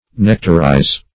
Search Result for " nectarize" : The Collaborative International Dictionary of English v.0.48: Nectarize \Nec"tar*ize\, v. t. [imp.